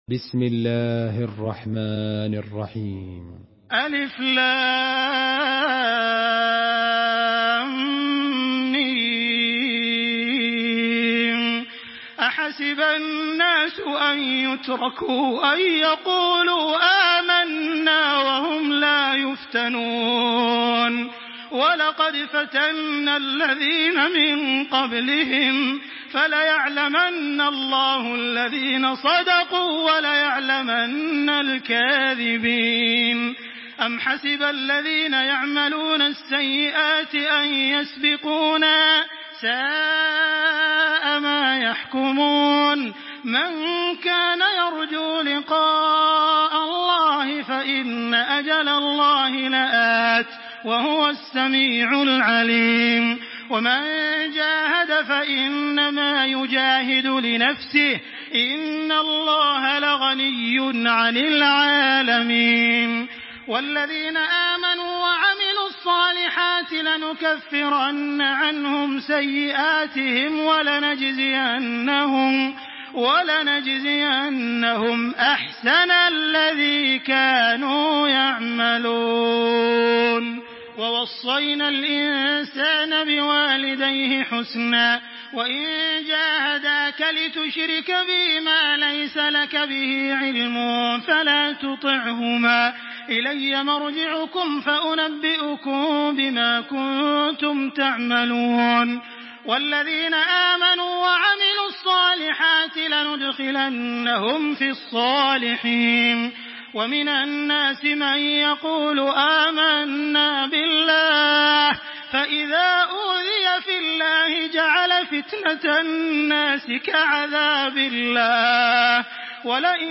سورة العنكبوت MP3 بصوت تراويح الحرم المكي 1426 برواية حفص
مرتل